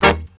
H03BRASS.wav